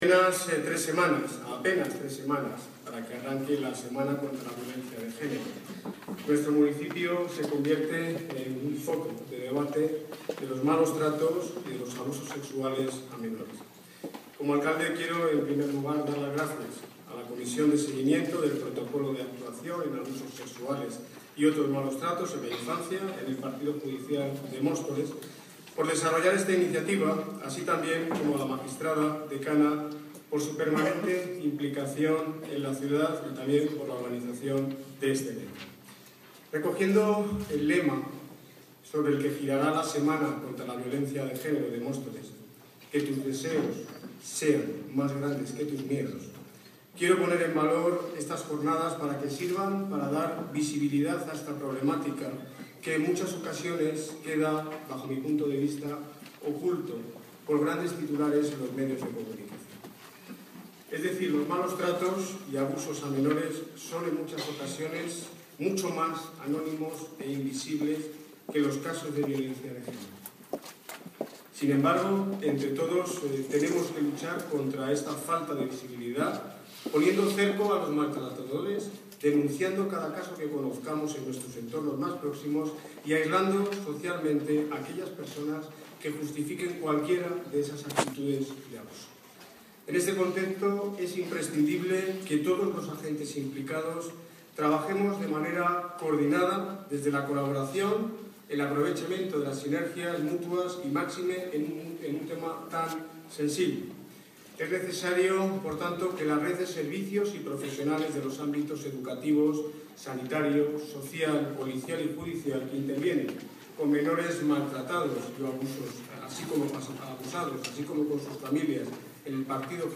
Audio - Daniel Ortiz (Alcalde de Móstoles) Sobre Jornadas Malos Tratos Menores